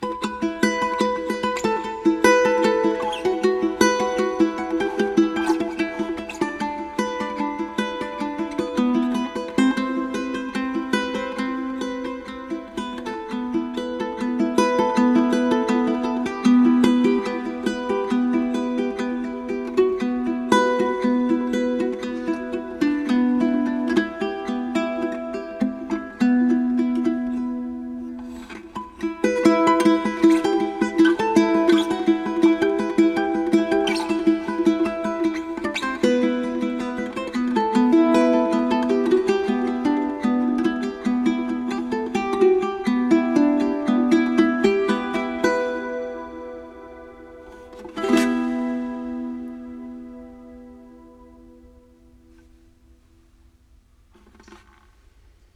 Plusieurs accordages possible, principalement comme le charango, une quarte plus bas. Instrument doux, qui se prête aux ambiances, et au jeu avec cordes à vide.
Ronroco.mp3